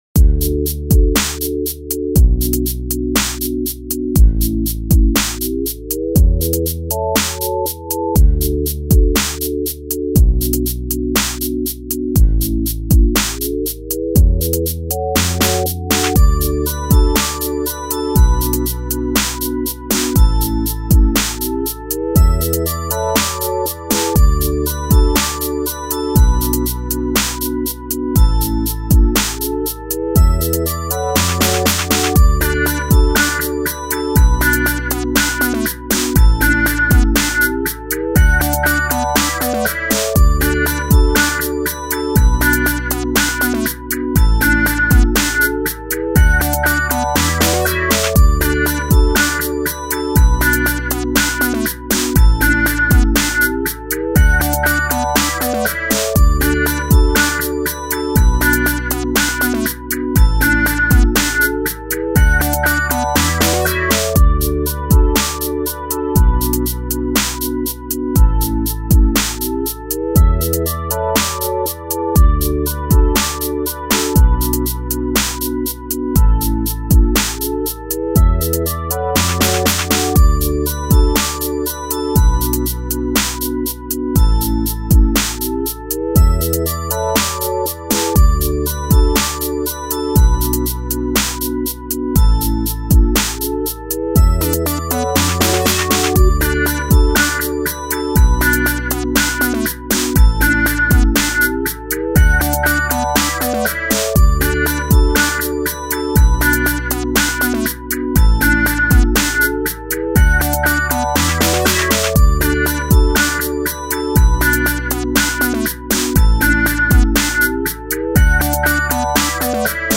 Hey friends, here is a feel good tune.
Filed under: Instrumental | Comments (4)
Totally catchy and sick beat.